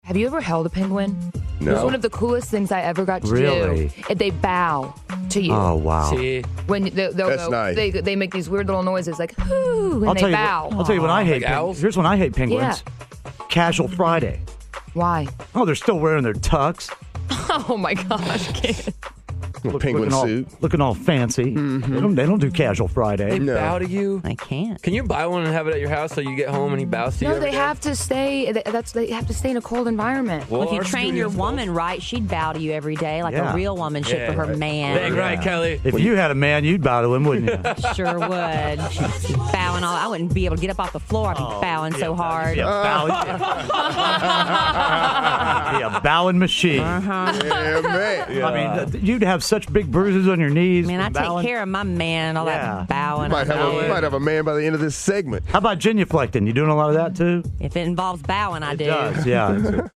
Watch Kellie Rasberry sarcastically compare herself to a penguin!